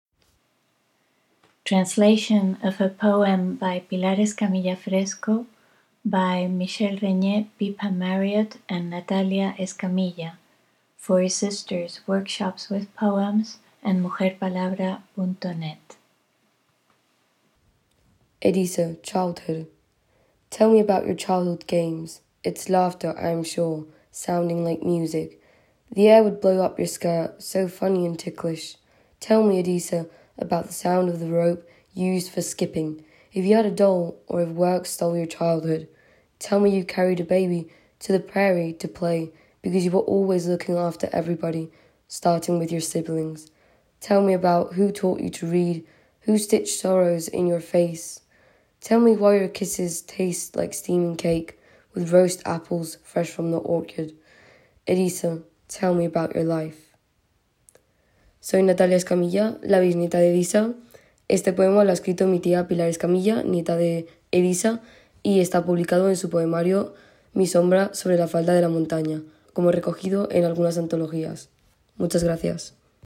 Los audios de ambos poemas, también autorizados, han sido creados como material complementario para la citada obra y publicados en Mujer Palabra